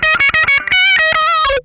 MANIC LICK.wav